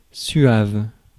Ääntäminen
Synonyymit doux velvétien Ääntäminen France: IPA: /sɥav/ Haettu sana löytyi näillä lähdekielillä: ranska Käännöksiä ei löytynyt valitulle kohdekielelle.